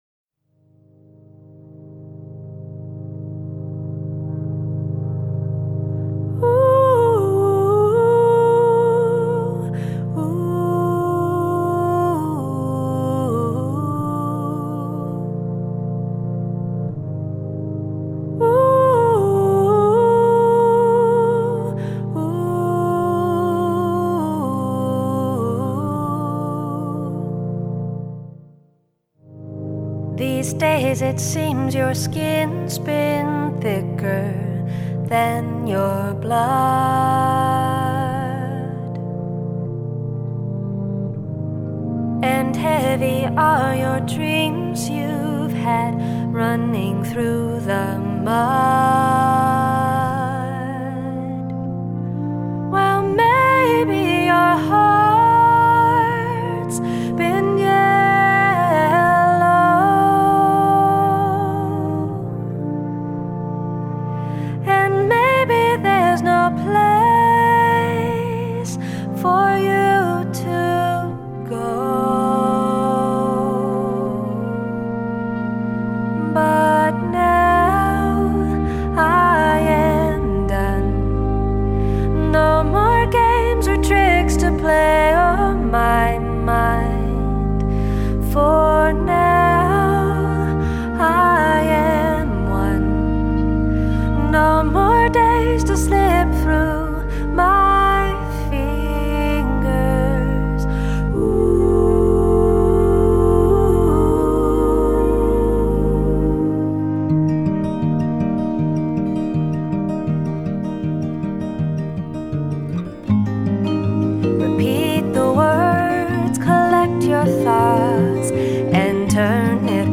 vibraphone, marimba, drums & percussion
background vocals & vocal arrangement
violin